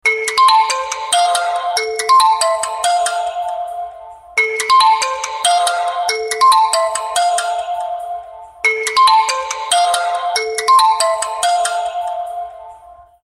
10 Sonnerie elle (258.89 Ko)
10-sonnerie-elle.mp3